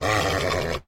mob / horse / angry1.ogg
angry1.ogg